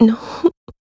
Ripine-Vox_Sad.wav